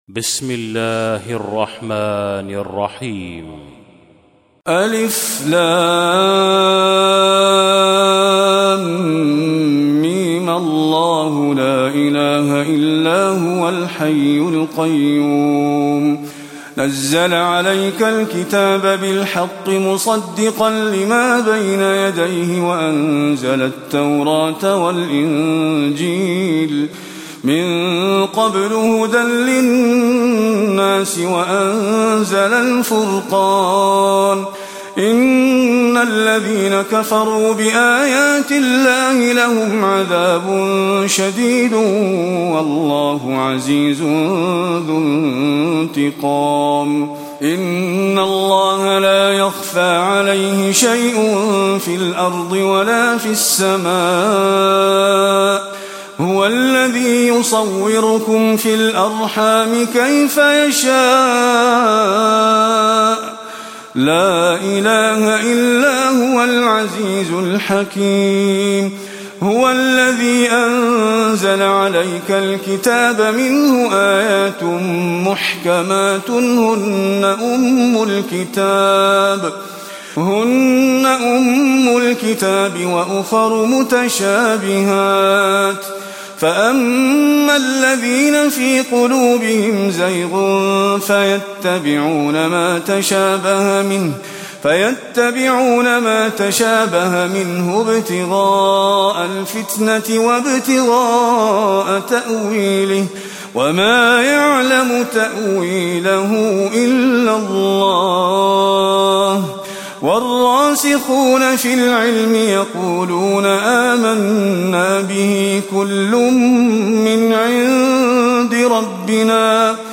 تلاوة سورة آل عمران من آية 1 إلى آية 32
تاريخ النشر ١ محرم ١٤٣٧ هـ المكان: المسجد النبوي الشيخ: فضيلة الشيخ محمد خليل القارئ فضيلة الشيخ محمد خليل القارئ سورة آل عمران (1-32)